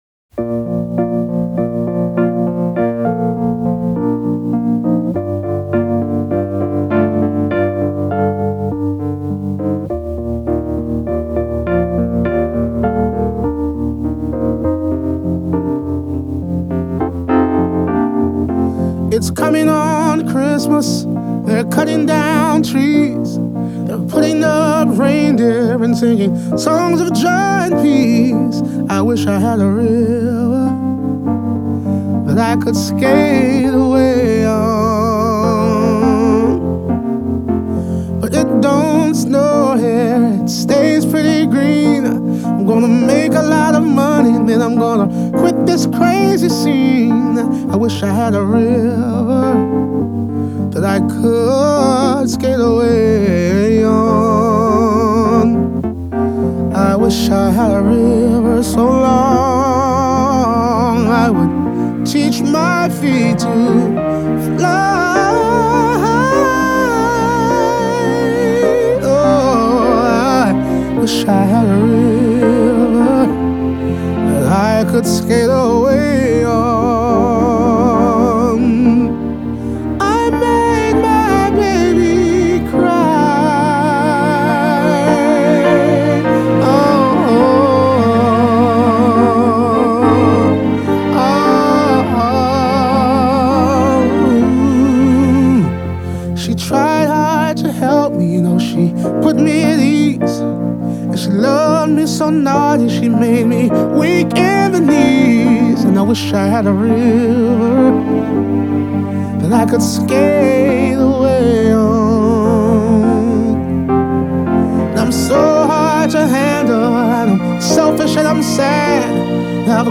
soul singer extraordinaire and co-host of The Voice